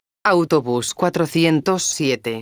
megafonias exteriores